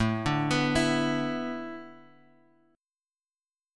Asus2sus4 chord